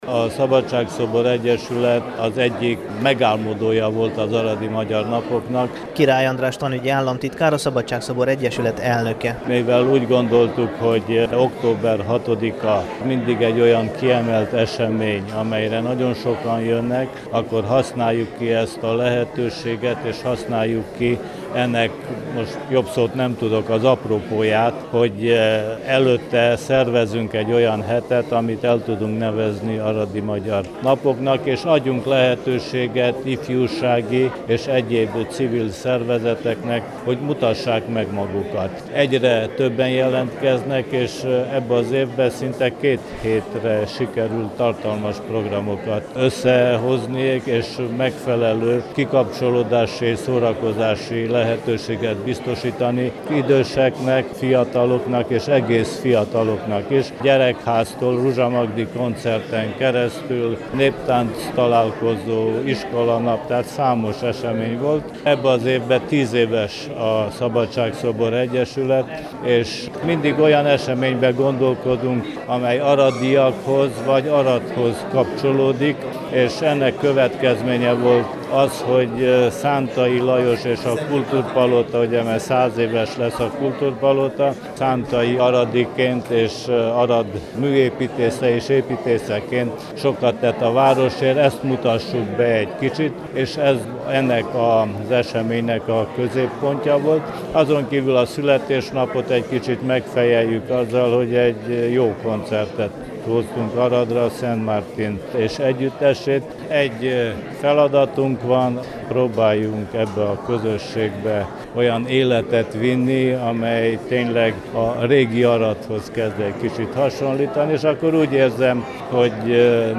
A rendezvényről a Temesvári Rádió pénteki kulturális műsora is beszámol.